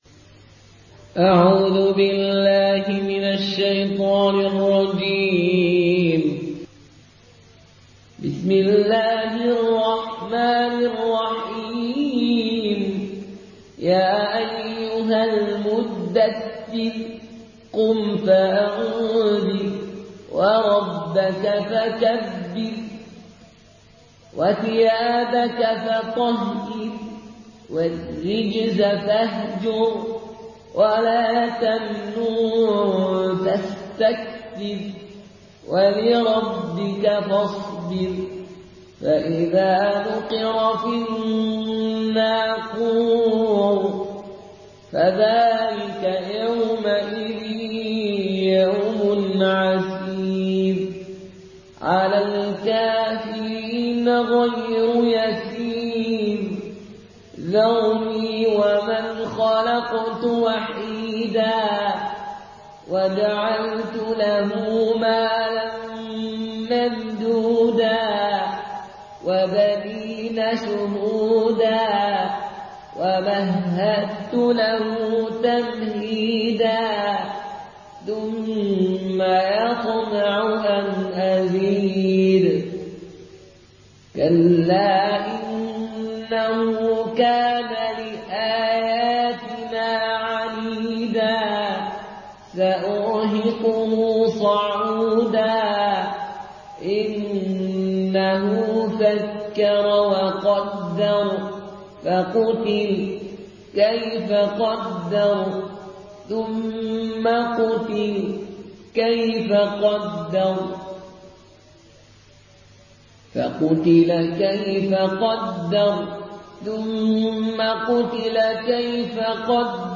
Une récitation touchante et belle des versets coraniques par la narration Qaloon An Nafi.